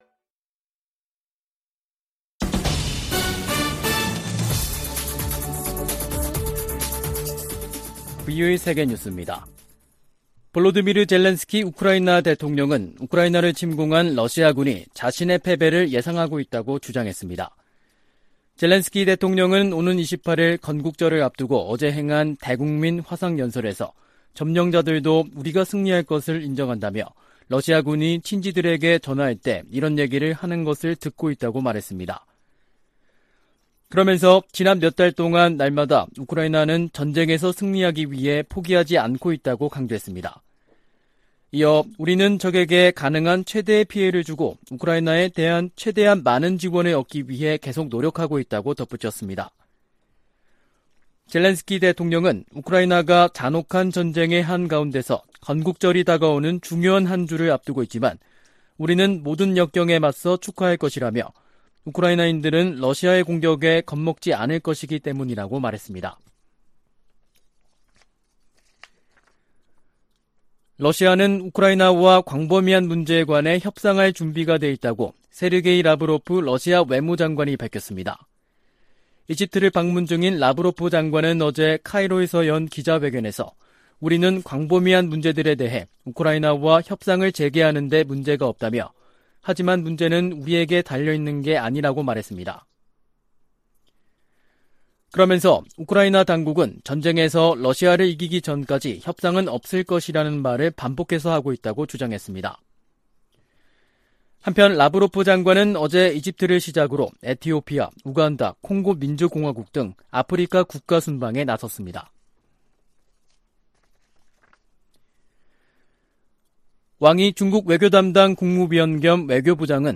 VOA 한국어 간판 뉴스 프로그램 '뉴스 투데이', 2022년 7월 25일 3부 방송입니다. 미 국무부의 빅토리아 눌런드 정무차관은 러시아와 북한의 밀착이 러시아의 고립을 보여주는 것이라고 평가했습니다. 일본과 아일랜드 정상이 북한의 탄도미사일 발사를 규탄하며 대량살상무기 완전 폐기를 촉구했습니다. 사이버 공격 대응을 위한 정부의 노력을 강화하도록 하는 ‘랜섬웨어 법안’이 미 하원 상임위원회를 통과했습니다.